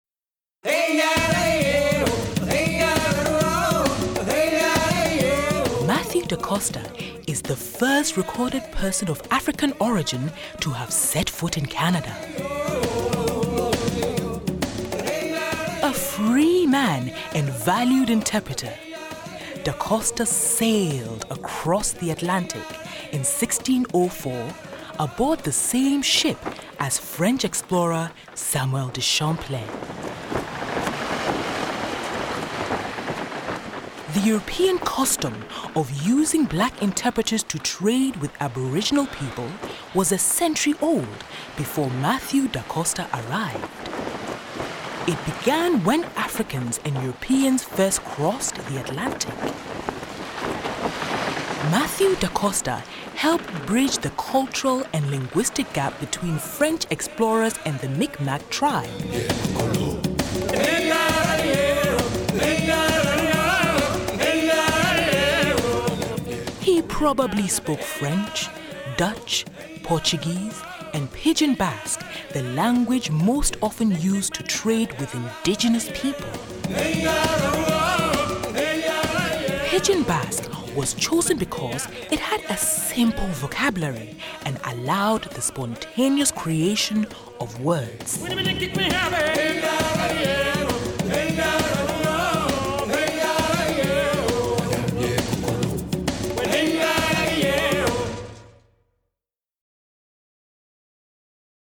Sage) Trad. Afr. dance – Kosinu – KOS B19
Narrator: